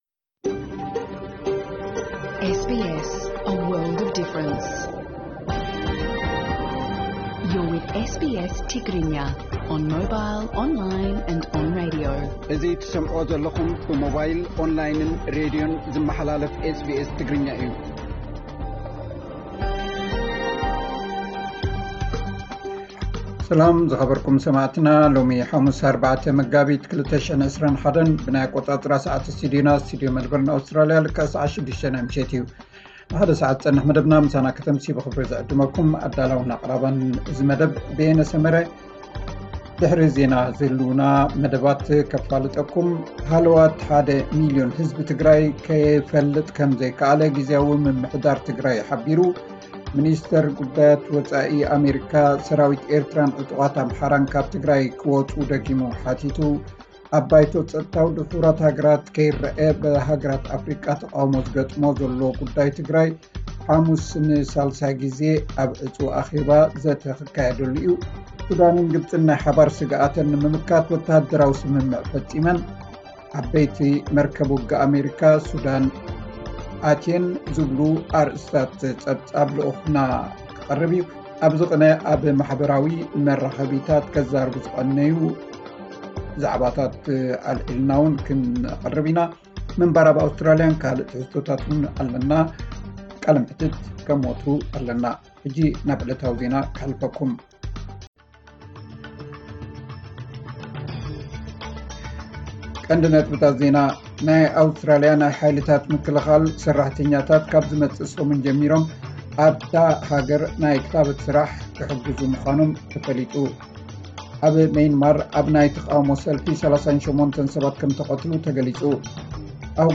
ዕለታዊ ዜና SBS ትግርኛ 04 መጋቢት 2021